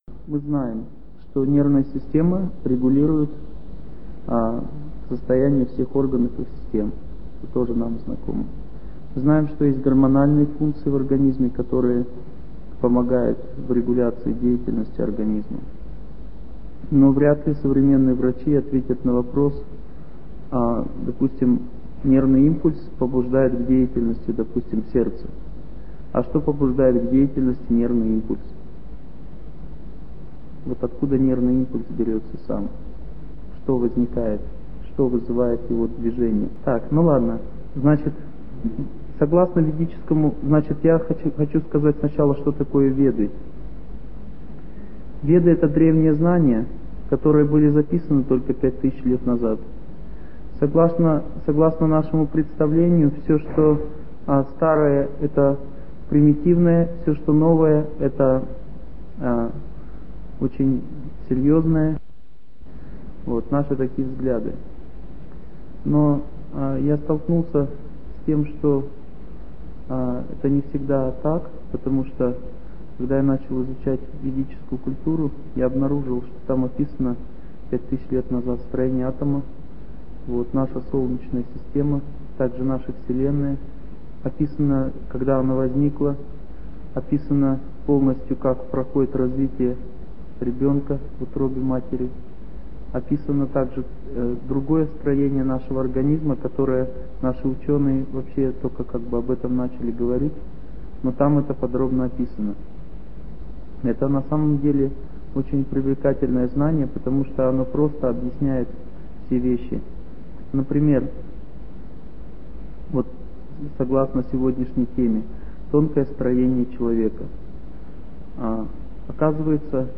Аудиокнига О здоровом образе жизни | Библиотека аудиокниг